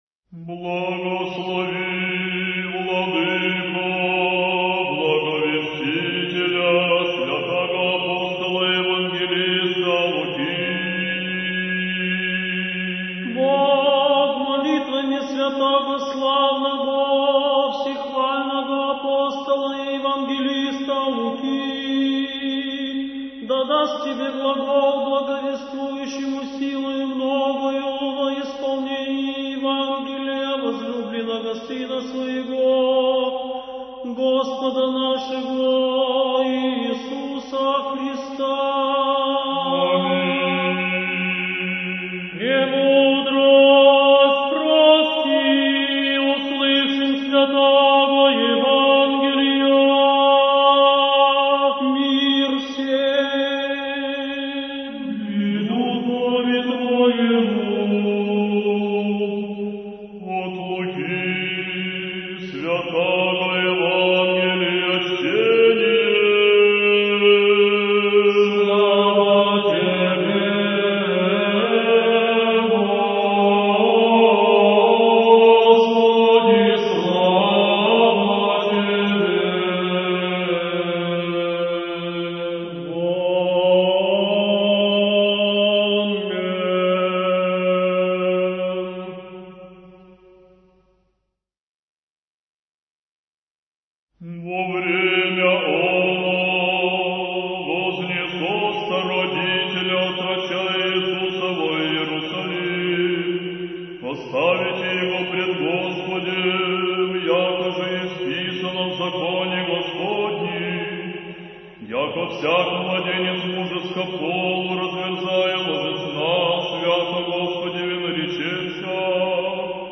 Архив mp3 / Духовная музыка / Русская / Ансамбль "Сретение" /